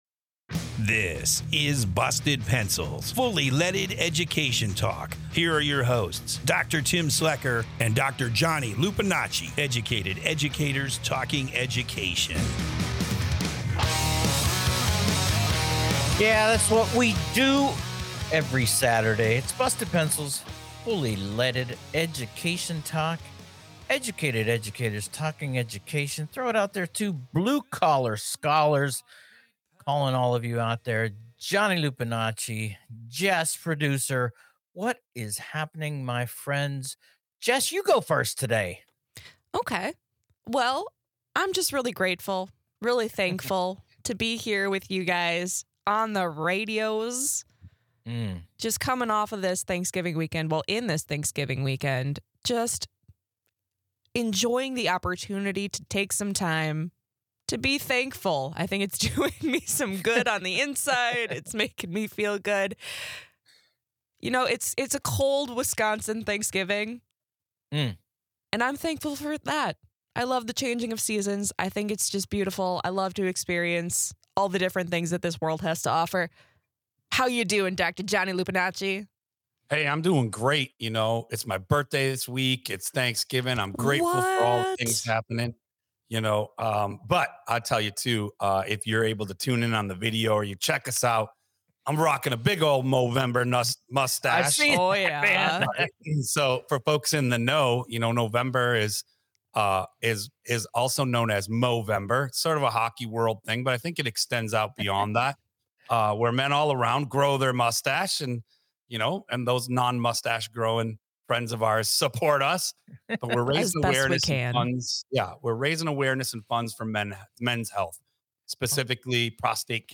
Or do we just need more than a binary system? Our educated educators kick the ideas around.